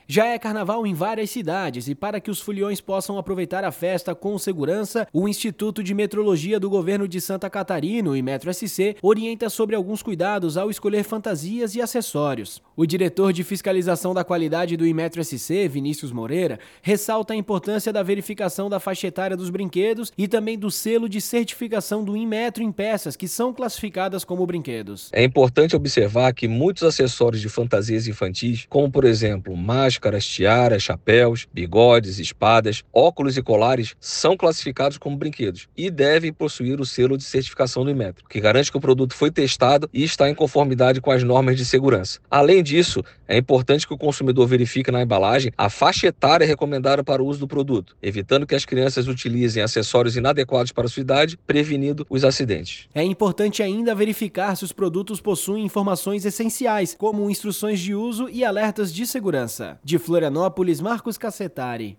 BOLETIM: CARNAVAL: Imetro-SC orienta consumidores na escolha das fantasias e acessórios